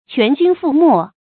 注音：ㄑㄨㄢˊ ㄐㄩㄣ ㄈㄨˋ ㄇㄛˋ
全軍覆沒的讀法